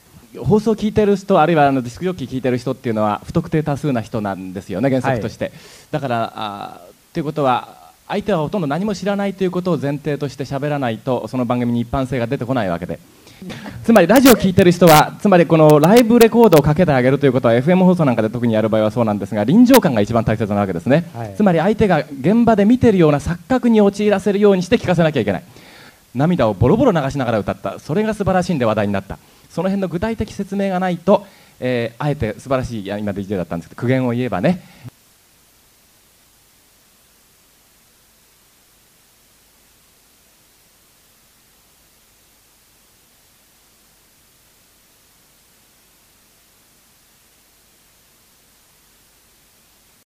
審査員 久米宏さんの総評（録音より）
DJ終了後、会場審査員のコメントに続き、久米宏さんから総評がありました。
久米宏さんのコメントは約2分に渡って頂きましたが、本記事では重要な部分を約30秒に編集して音声として下記にアップしました。
※本コメントは当時の録音をもとに、一部を抜粋・編集して掲載しています。